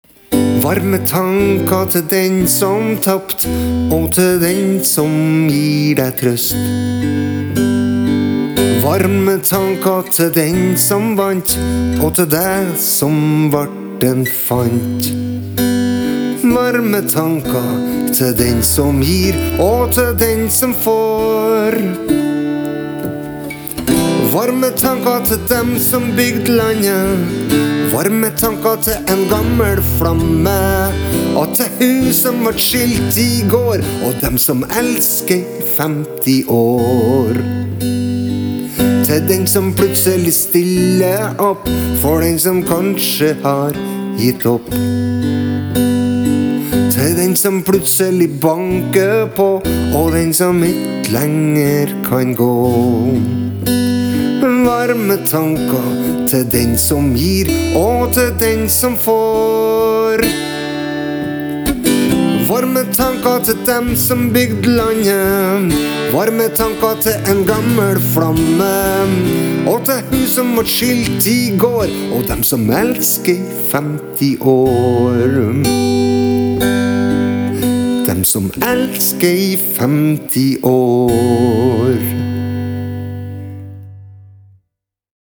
Bare sang og gitar. Varme tanka går til de fleste av oss.